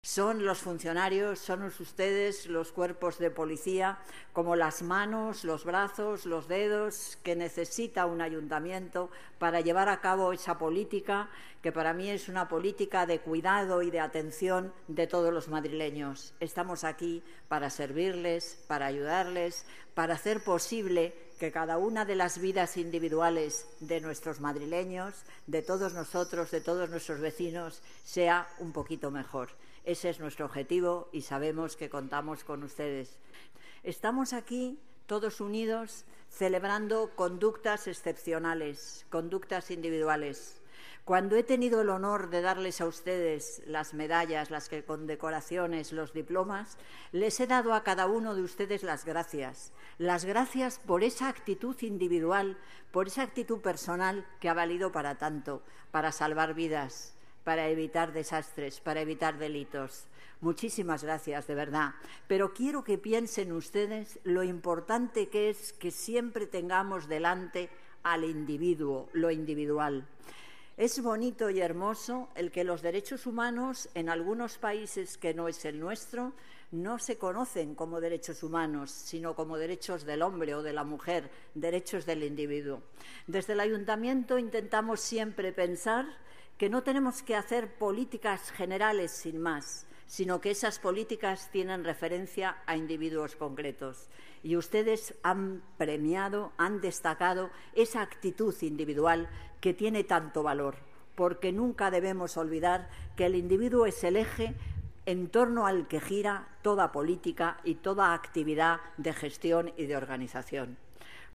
Nueva ventana:Declaraciones alcaldesa Madrid, Manuela Carmena: celebración fiesta San Juan Bautista, patrón PM